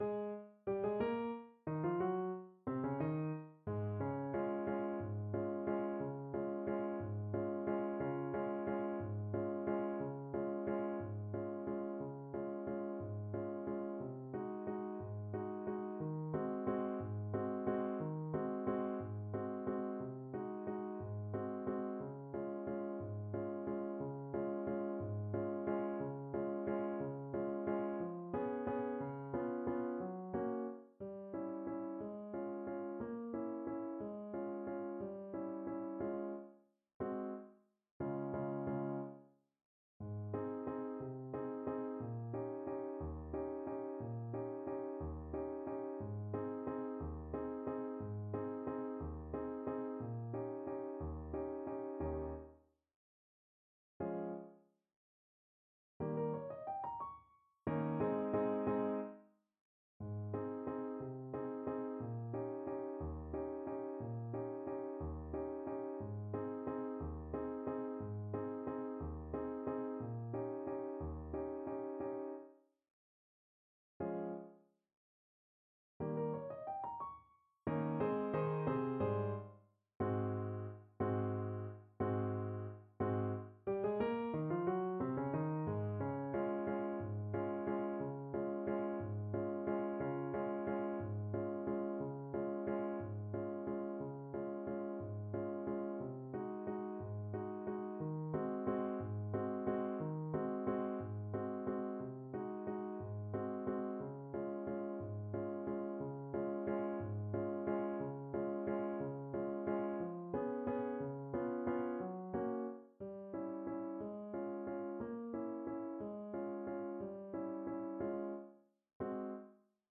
Alto Saxophone version
3/4 (View more 3/4 Music)
Tempo di Waltz (.=c.64)